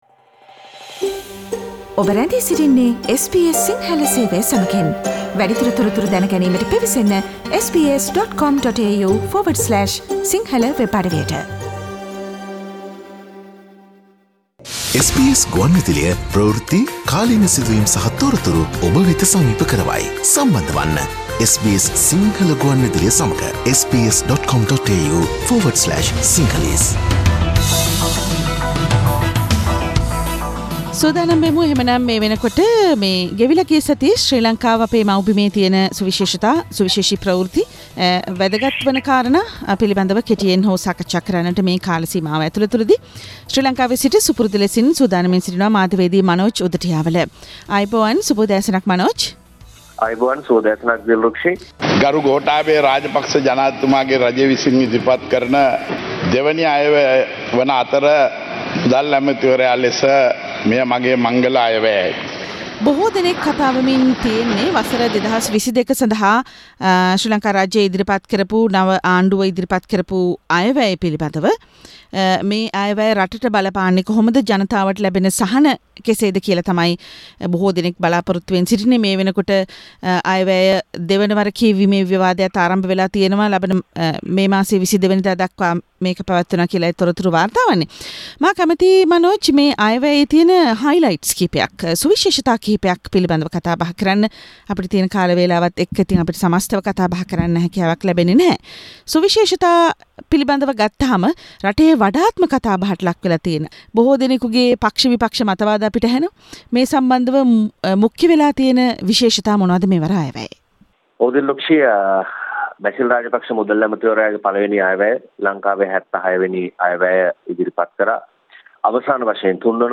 Is Ali Sabri behind the power downgrade of 'One Country One Nation presidential committee? Sri Lankan news wrap